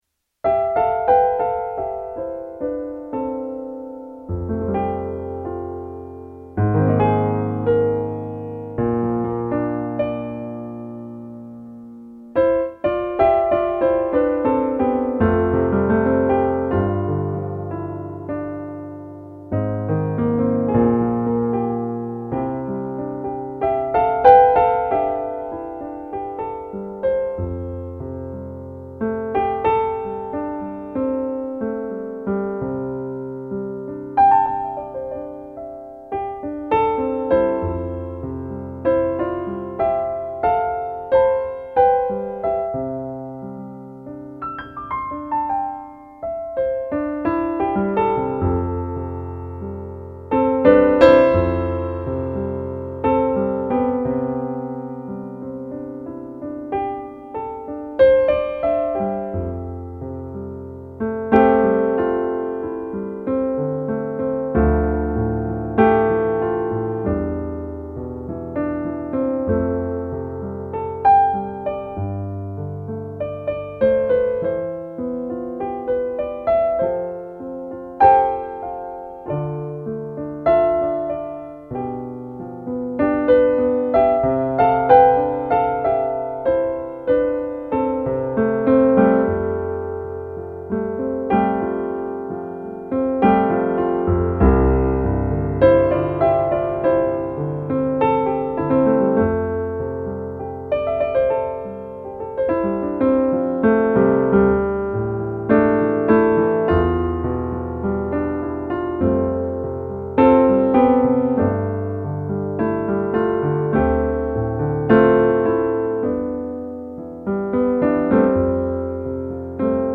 While my emotions shifted throughout, I maintained a chill vibe, though some moments carried a different, dynamic energy.
Let me know your thoughts on this mix of energy and chill!
However, you might notice that not all of the music carried the typical “chill” mood—some parts felt different, more dynamic, or even sad.